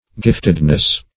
giftedness - definition of giftedness - synonyms, pronunciation, spelling from Free Dictionary
Giftedness \Gift"ed*ness\, n. The state of being gifted.